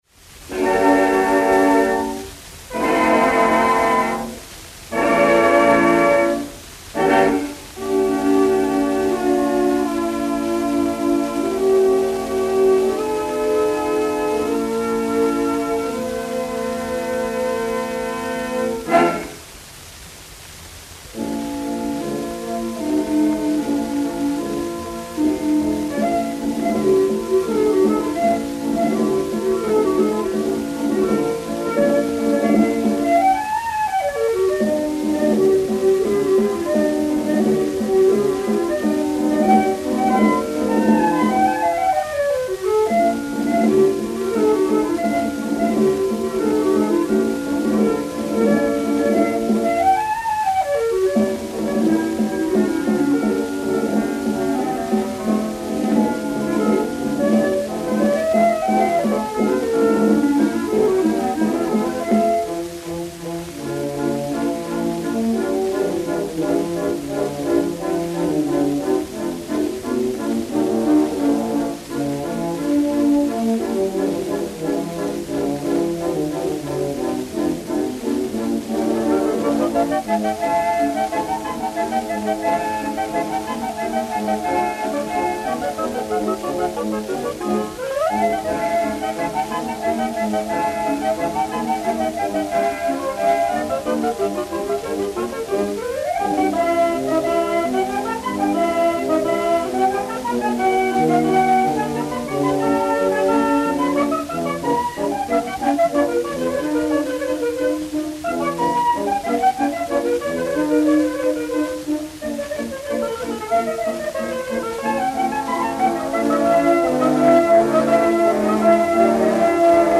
Suite d'orchestre des Deux Pigeons
Musique de la Garde Républicaine